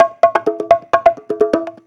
Conga Loop 128 BPM (3).wav